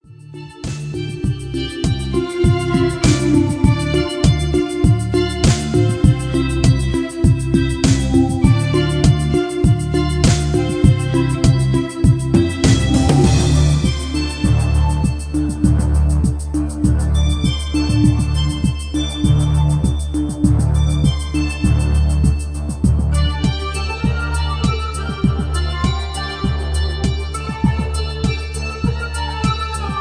Performed on synthesizers.
Tempo of 100 bpm
Key of Dm